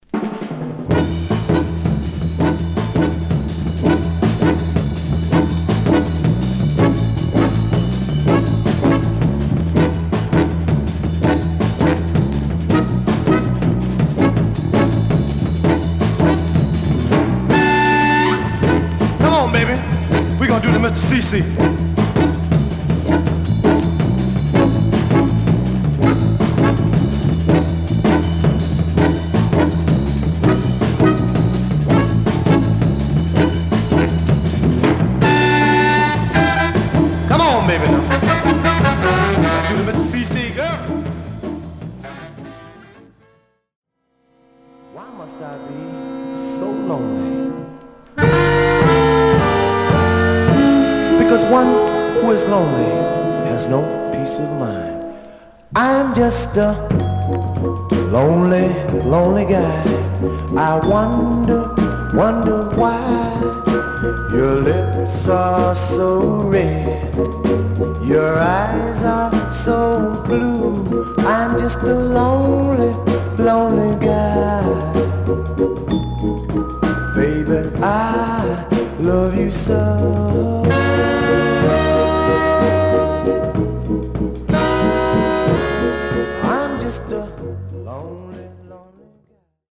Stereo/mono Mono
Funk